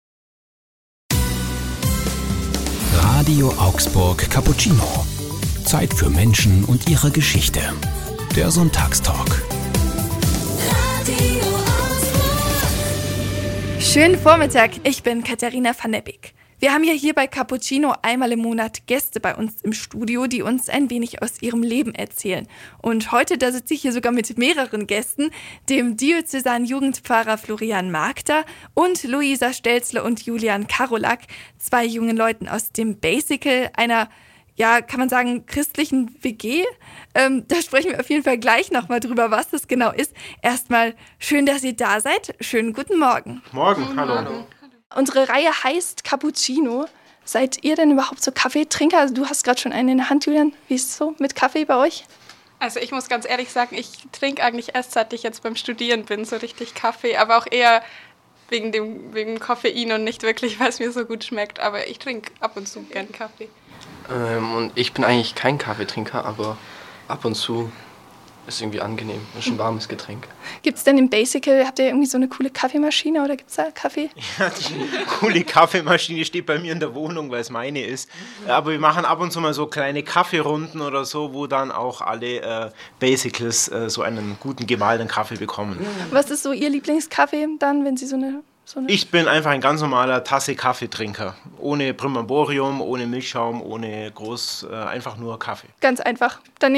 In der zweiten Folge unserer neuen Talkreihe „Cappuccino“ stehen